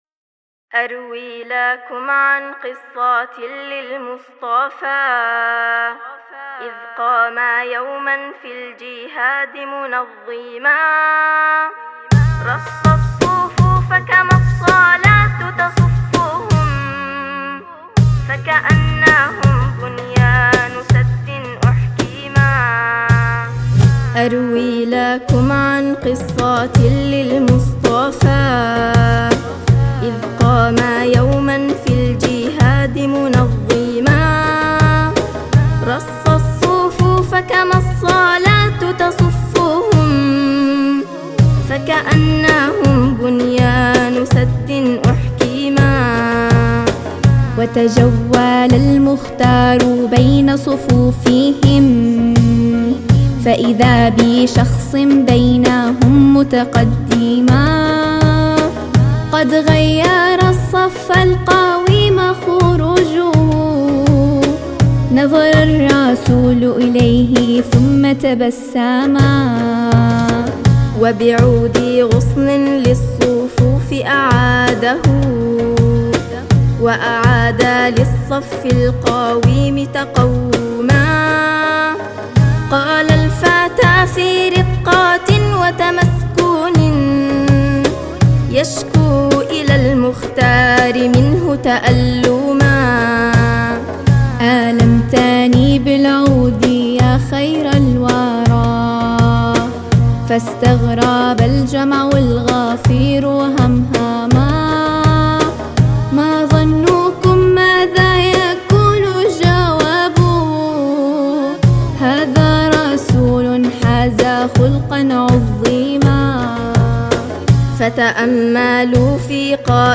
كما أنها من الأناشيد الدينية المشهورة